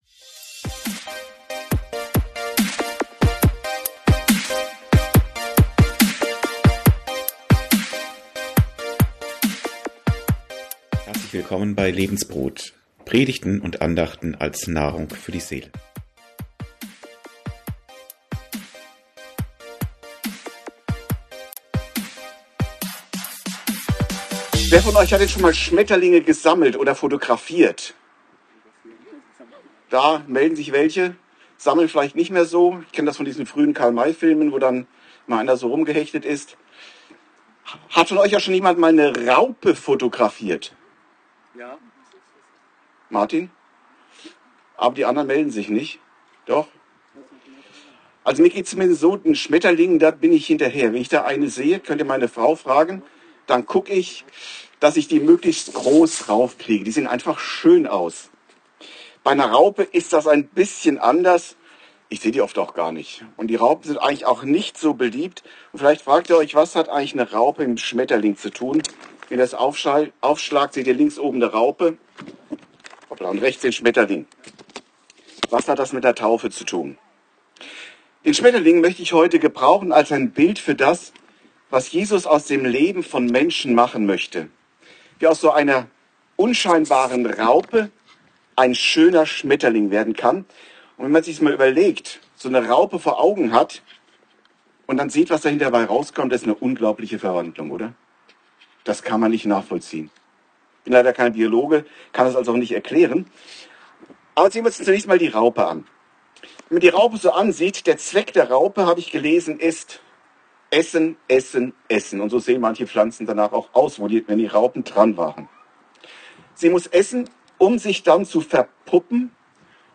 Predigt
im Rahmen eines Taufgottesdienstes am Lohner See, Grafschaft Bentheim, September 2025. Anhand der Transformation von einer Raupe zum Schmetterling wird die Verwandlung des Menschen nach seine Wiedergeburt deutlich gemacht.